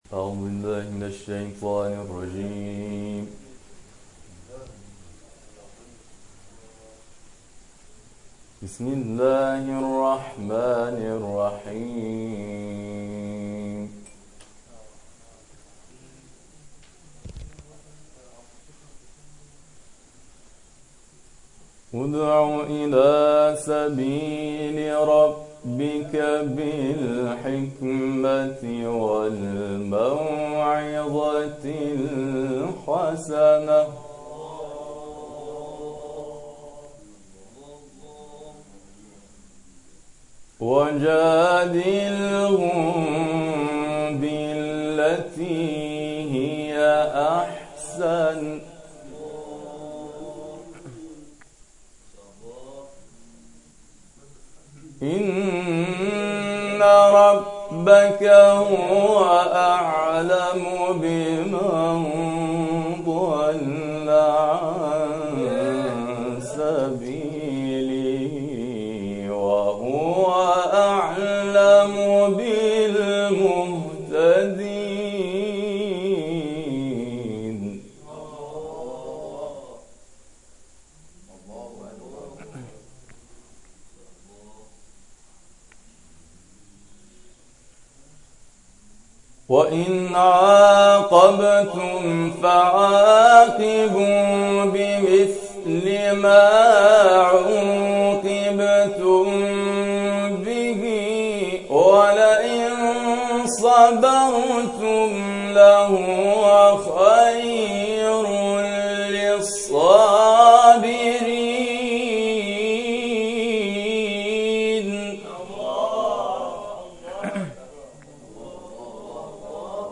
در ادامه تلاوت های این جلسه ارائه می‌شود.